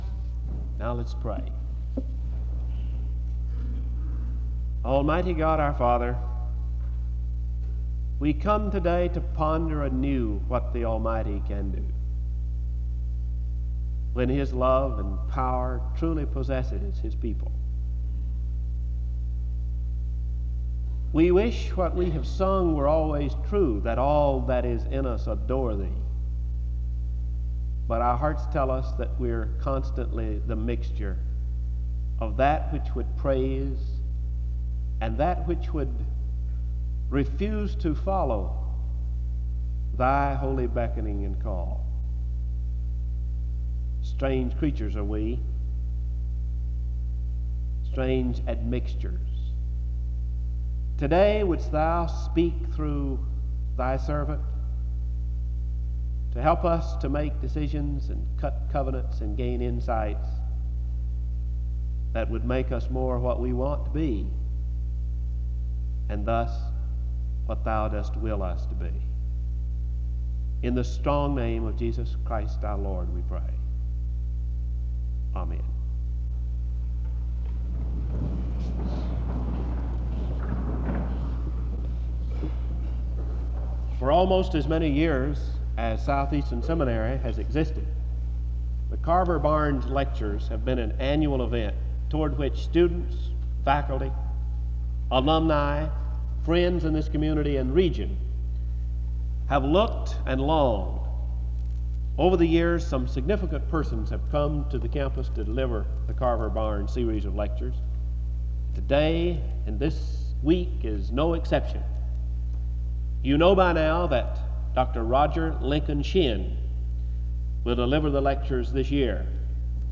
SEBTS Carver-Barnes Lecture